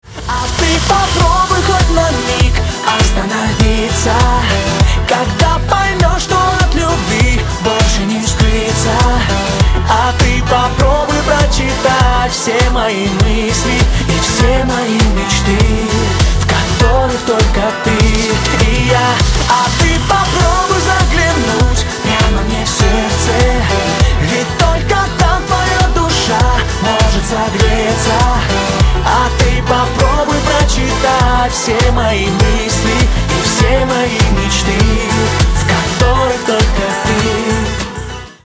поп
dance